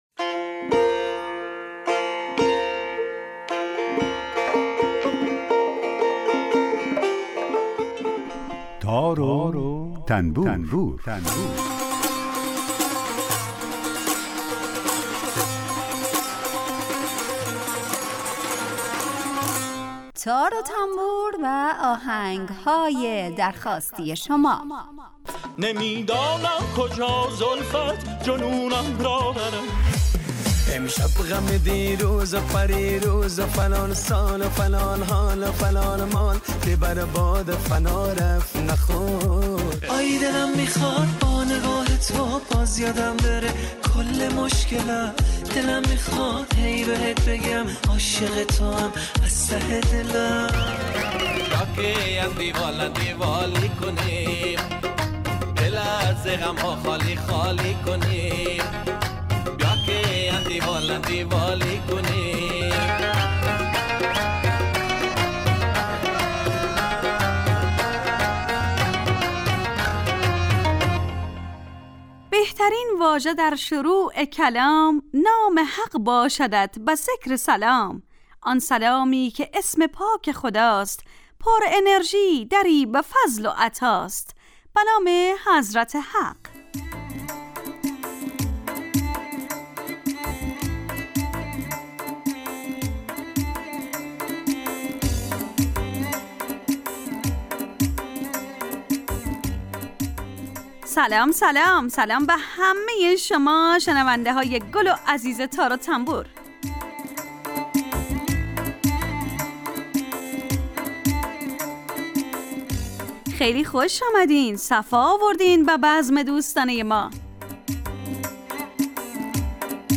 برنامه ای با آهنگ های درخواستی شنونده ها
در این برنامه هر روز یه آیتم به نام در کوچه باغ موسیقی گنجانده شده که به معرفی مختصر ساز ها و آلات موسیقی می‌پردازیم و یک قطعه بی کلام درباره همون ساز هم نشر میکنیم